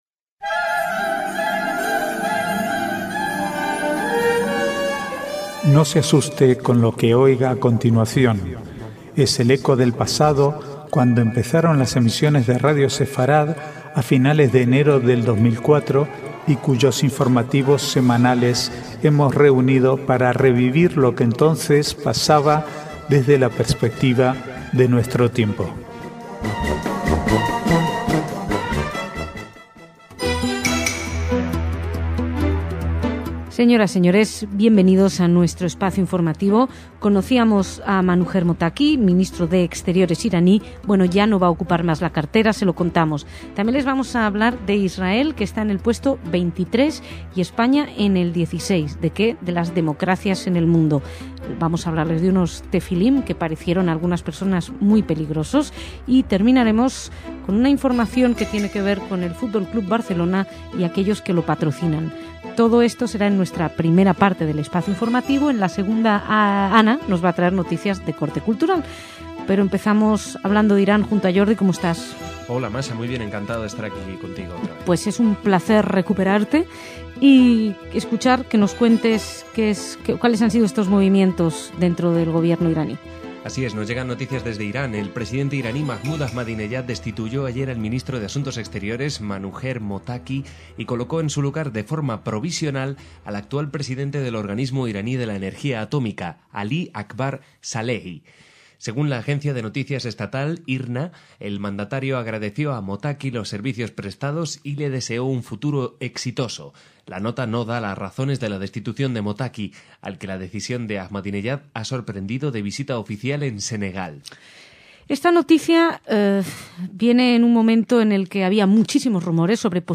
Archivo de noticias del 14 al 17/12/2010